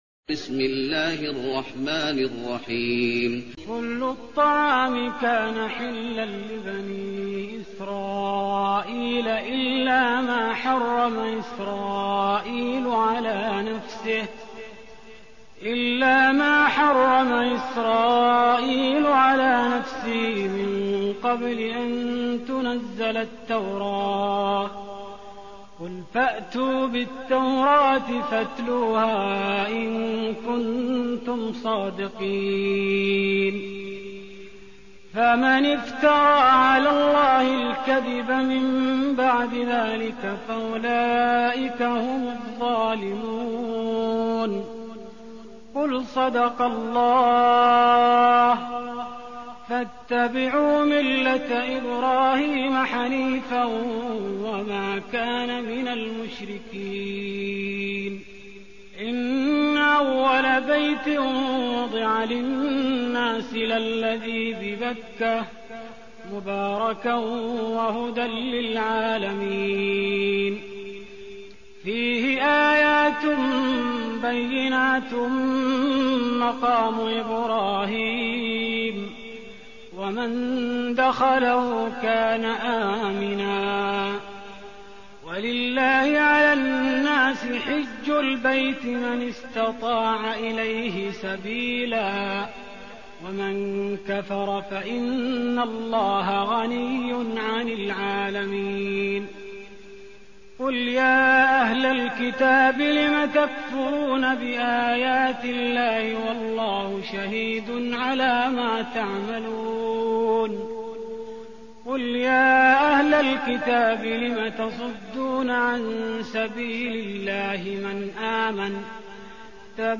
تهجد رمضان 1416هـ من سورة آل عمران (93-168) Tahajjud Ramadan 1416H from Surah Aal-i-Imraan > تراويح الحرم النبوي عام 1416 🕌 > التراويح - تلاوات الحرمين